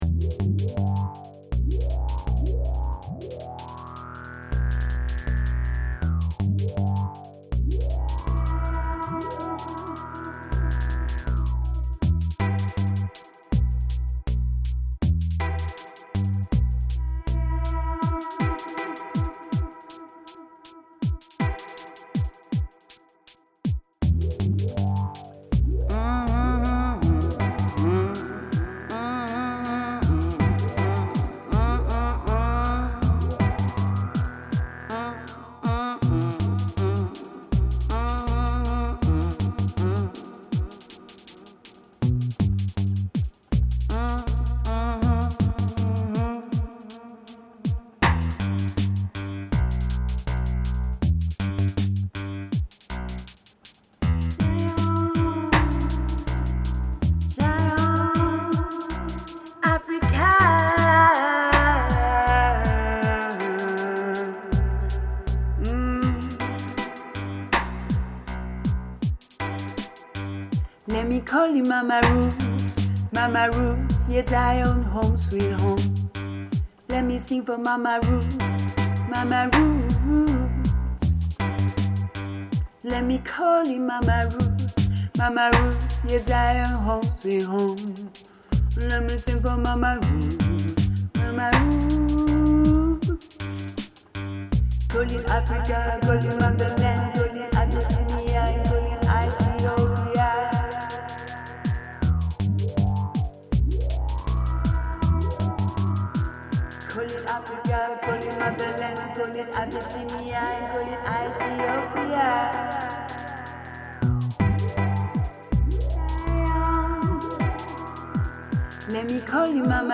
This London Reggae Singer is a One-Woman Band.